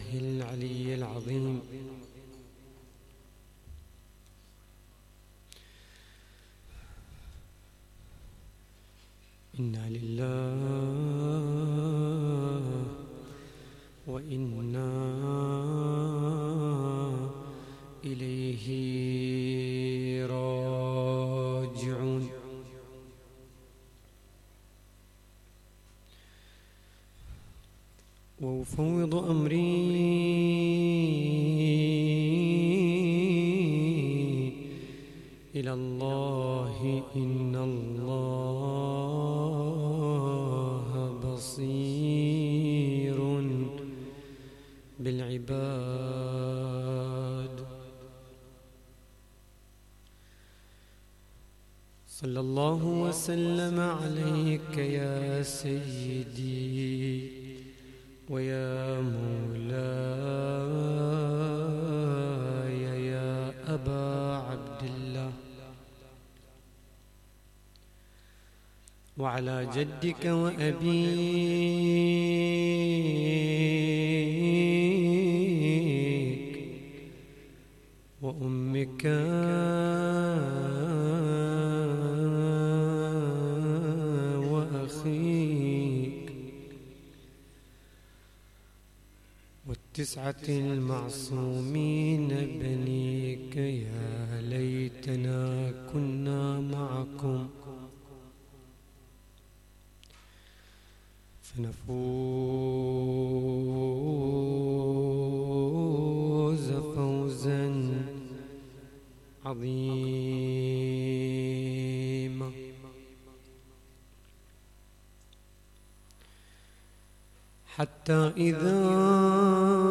تغطية صوتية: يوم ثالث محرم 1440هـ | مأتم أنصار الحسين (ع)